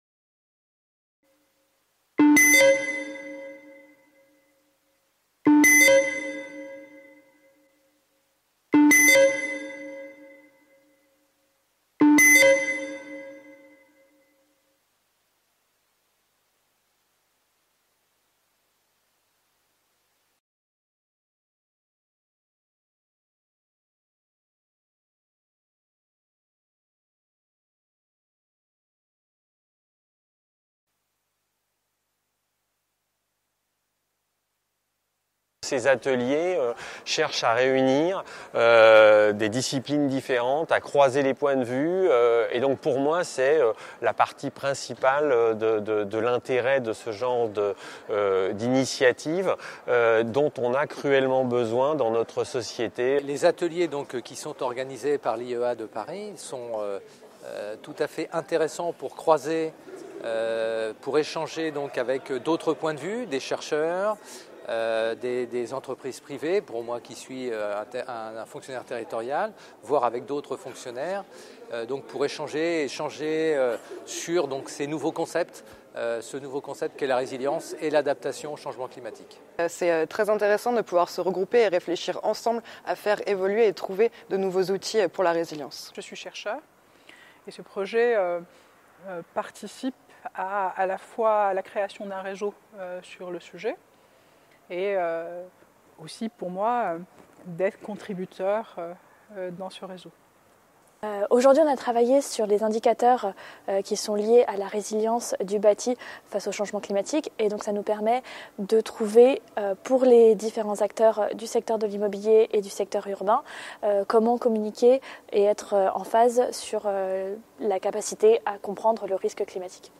"micro-trottoir" workshops dans le cadre du cycle résilience et innovation territoriale | Canal U